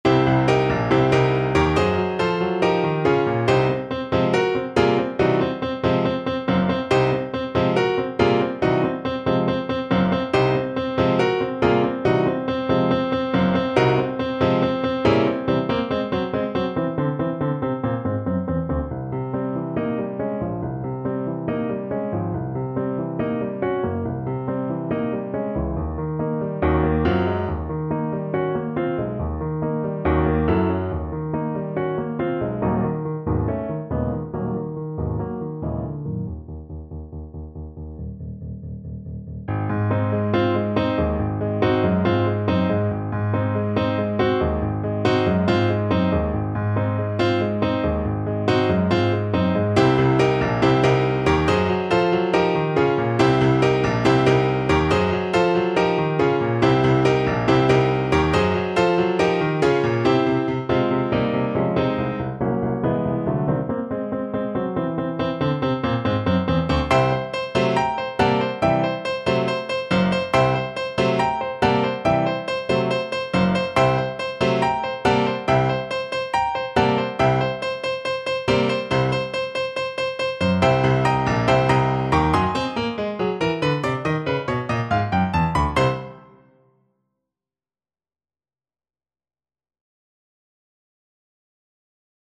4/4 (View more 4/4 Music)
Fast and Forceful = c. 140
Jazz (View more Jazz Alto Recorder Music)